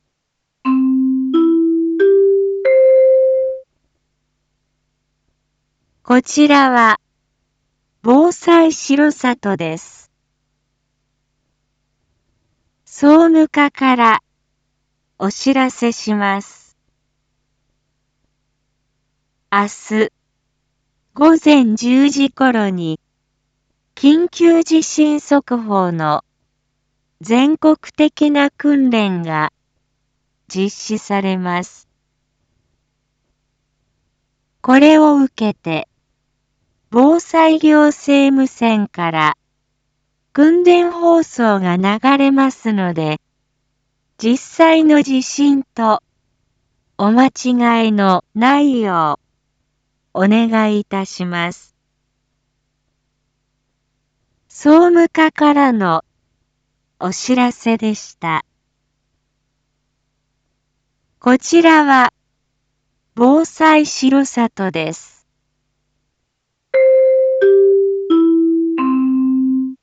Back Home 一般放送情報 音声放送 再生 一般放送情報 登録日時：2022-11-01 19:01:11 タイトル：前日・全国瞬時警報システム訓練 インフォメーション：こちらは、防災しろさとです。